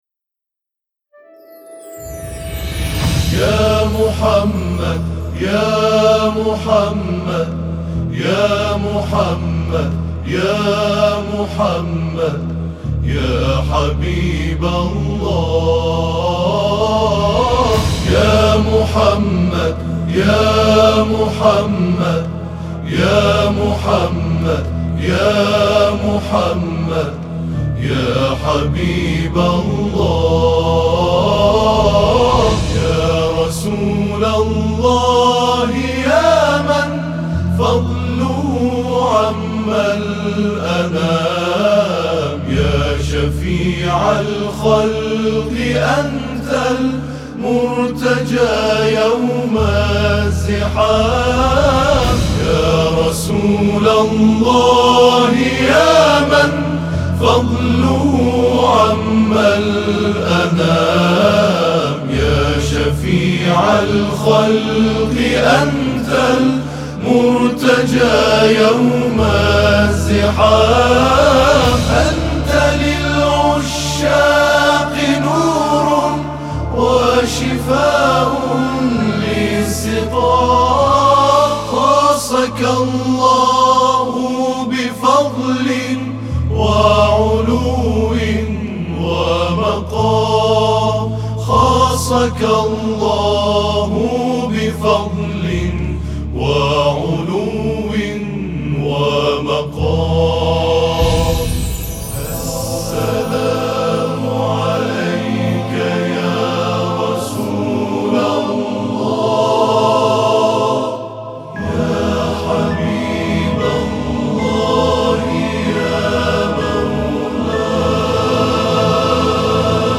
این‌ گروه با سابقه‌ای که طی این سال‌ها به دست آورده به گروهی شناخته شده در عرصه تواشیح تبدیل شده و در زمره گروه‌هایی است که توليد آثار به صورت آكاپلا و چندصدايی در قالب هنر تواشيح را در كارنامه خود دارد.